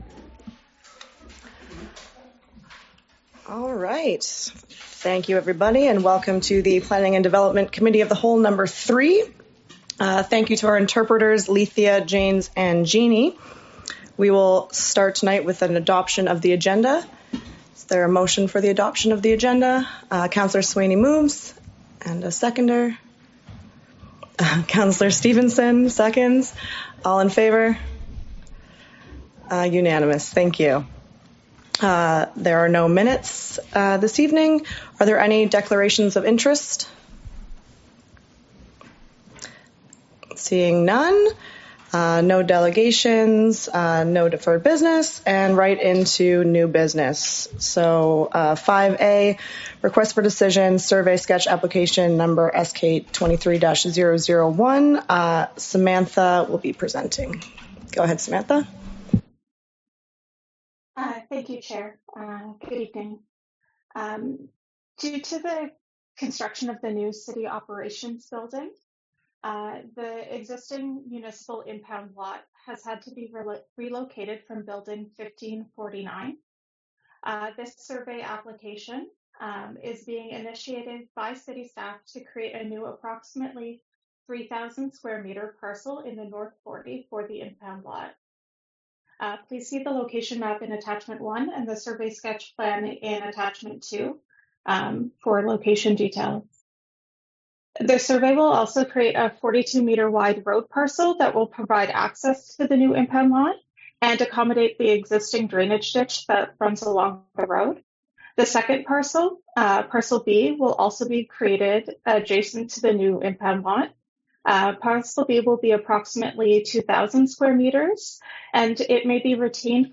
Planning and Development Committee of the Whole Meeting # 03 | City of Iqaluit